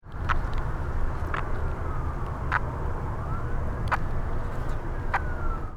Release Calls
Sound This is a 5 second recording of the release calls, or grunts, of a Northern Leopard Frog.